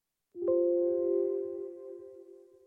windows10_sound_n7HrbF8.mp3